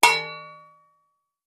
Free Samples : samples de effects .Efectos especiales,sonidos extrańos..